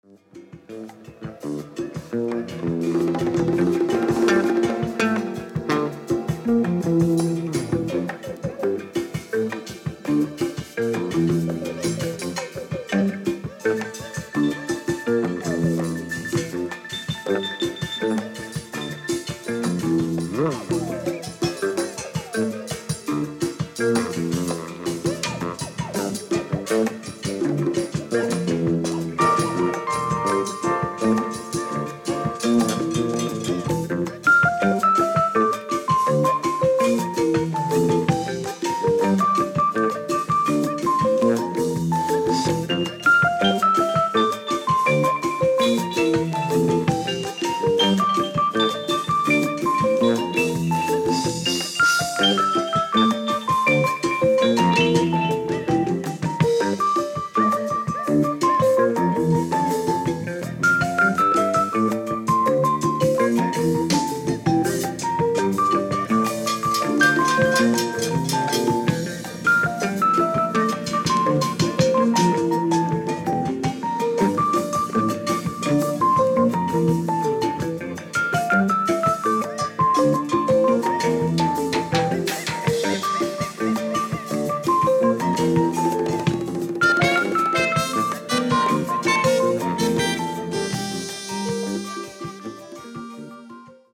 Voice ,Percussion
Electric Bass
Drums